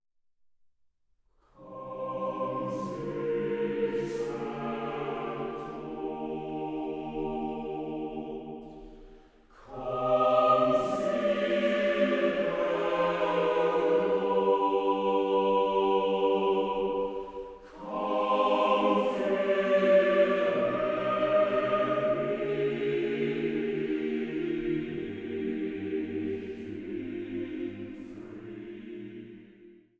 Vokalimprovisation